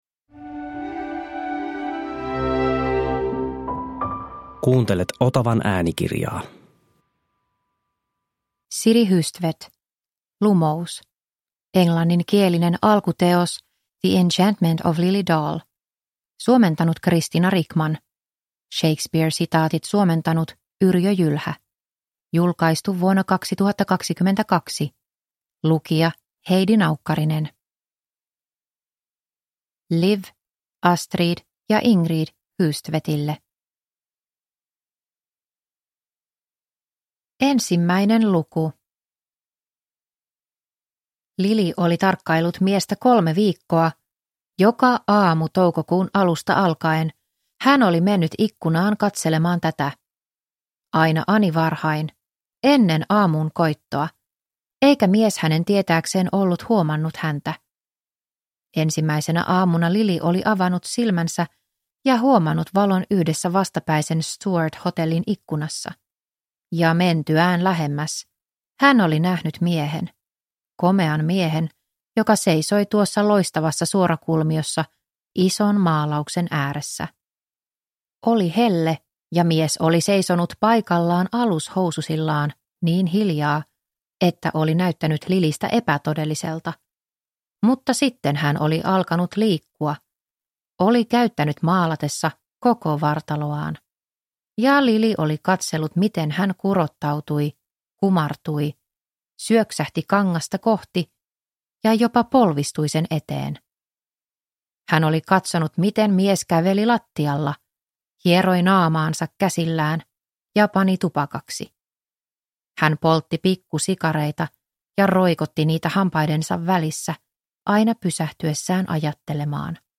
Lumous – Ljudbok – Laddas ner